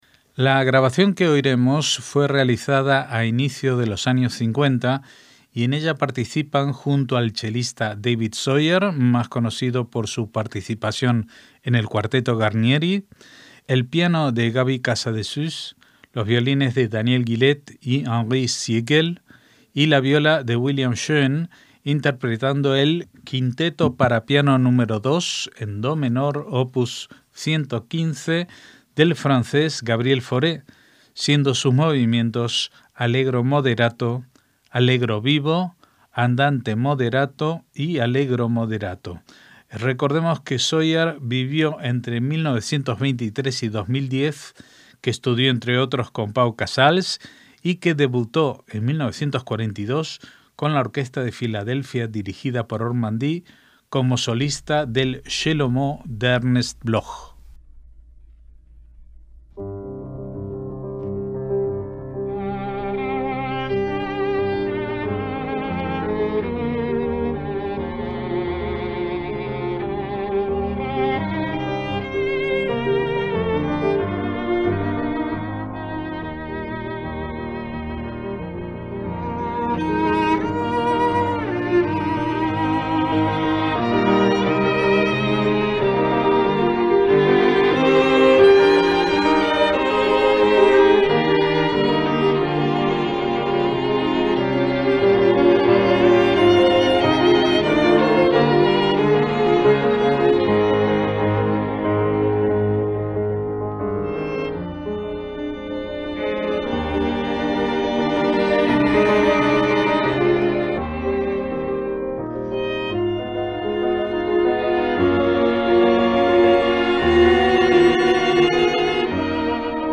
MÚSICA CLÁSICA - La grabación que oiremos fue realizada a inicio de los años 50
piano
violines
viola
Quinteto para piano
en do menor